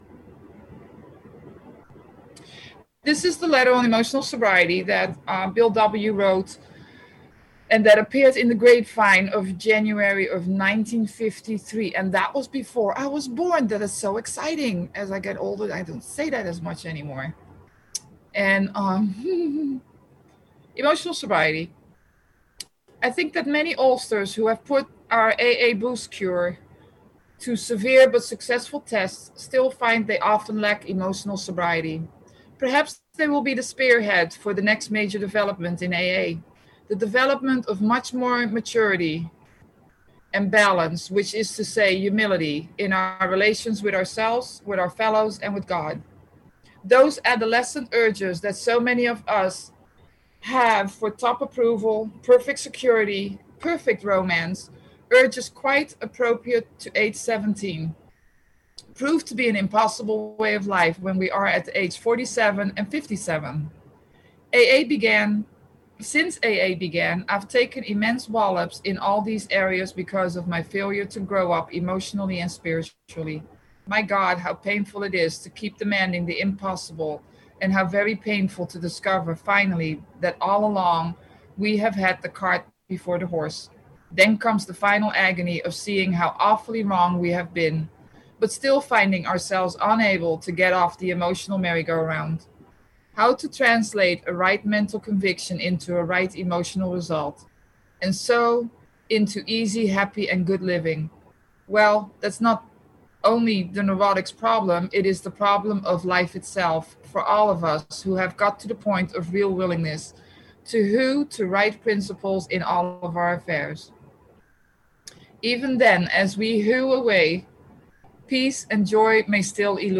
Emotional Sobriety Workshop